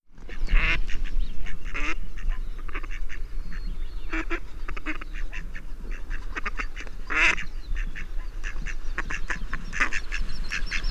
Sounds of the Escondido Creek Watershed
Mallard
ML - Mallard - edited.wav